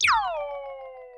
mention_received.ogg